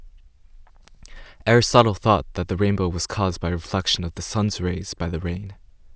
Speech !!!